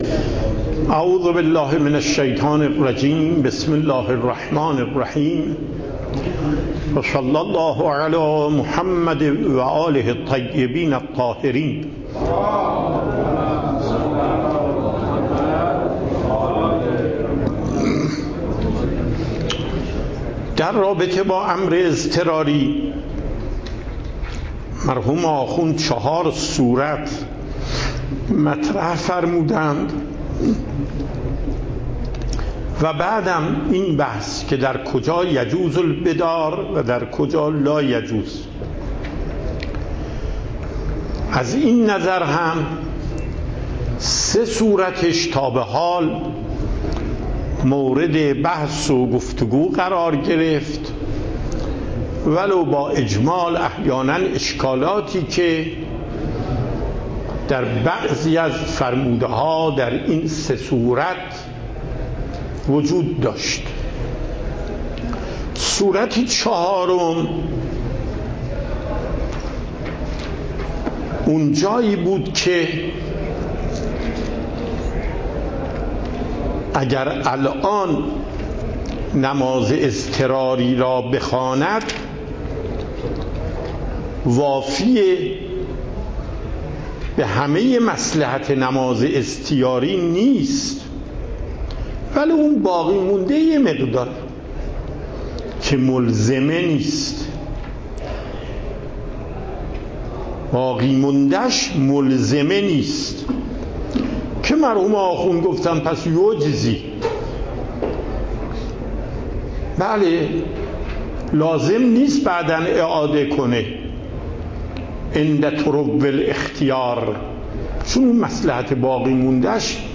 صوت و تقریر درس پخش صوت درس: متن تقریر درس: ↓↓↓ تقریری ثبت نشده است.
درس اصول آیت الله محقق داماد